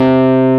RHODES2H C3.wav